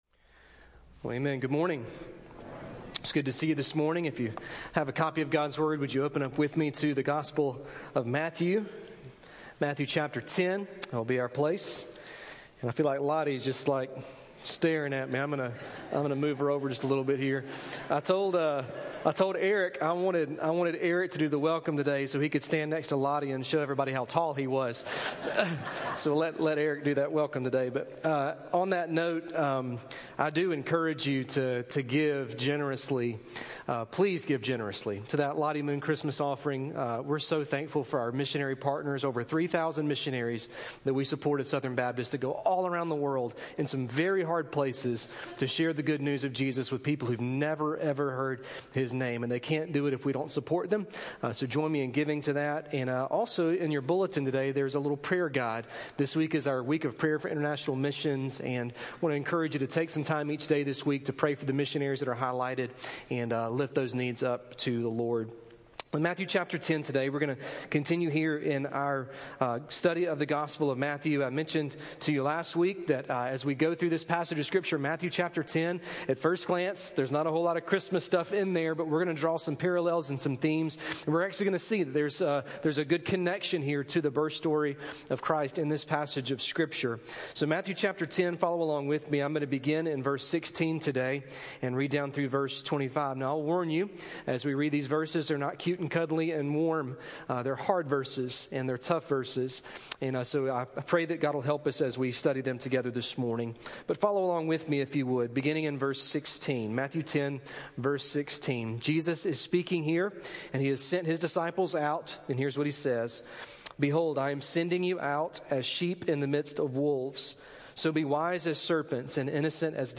Message: "Why the Cross?"